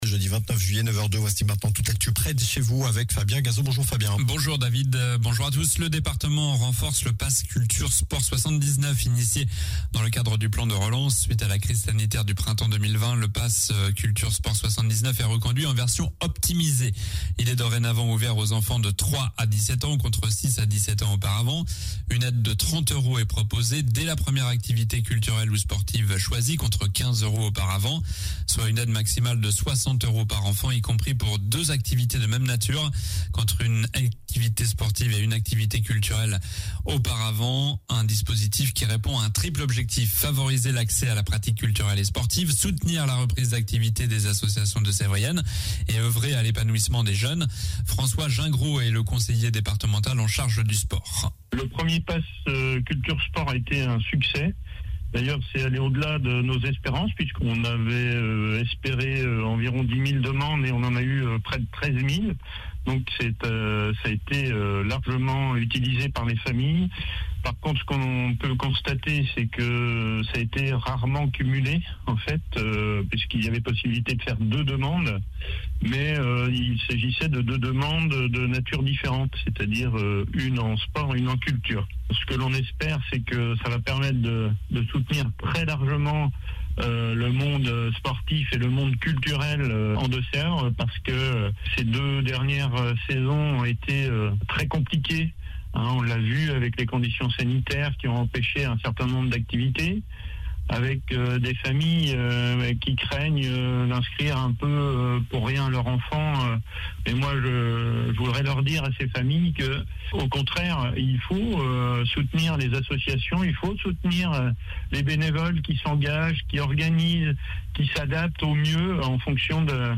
Journal du jeudi 29 juillet (matin)